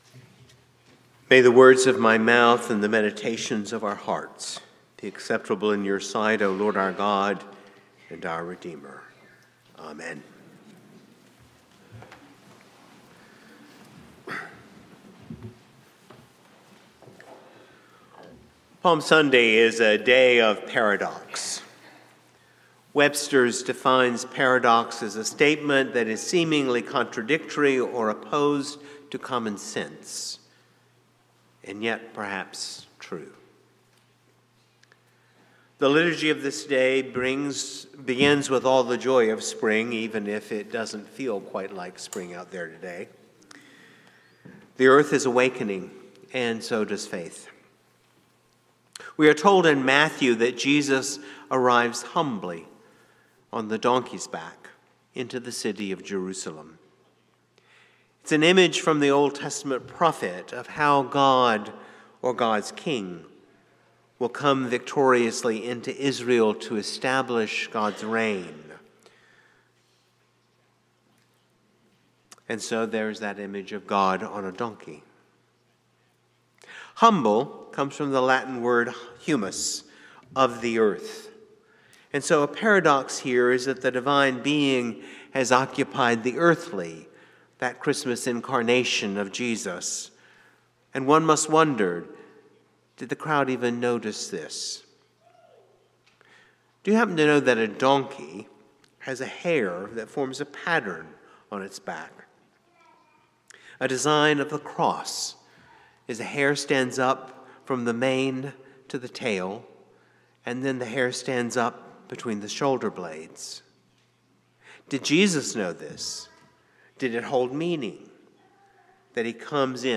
St-Pauls-HEII-9a-Homily-29MAR26.mp3